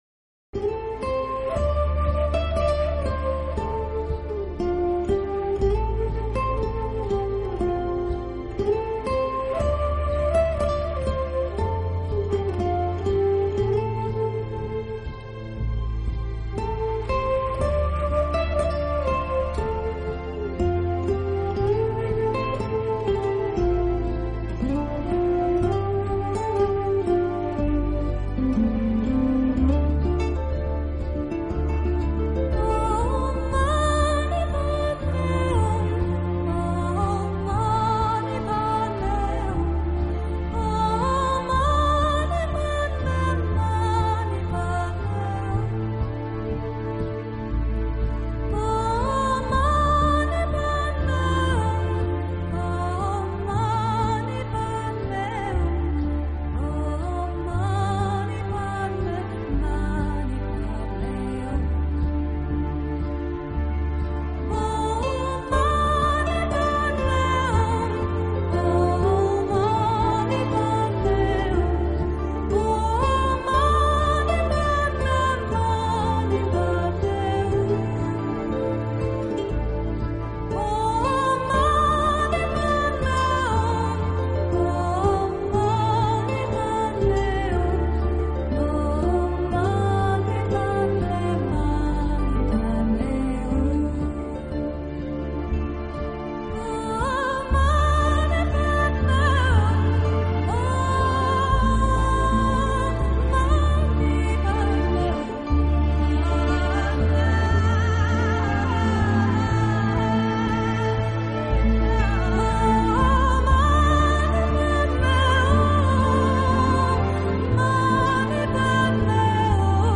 【纯音乐】
音乐风格： New Age / Meditative / World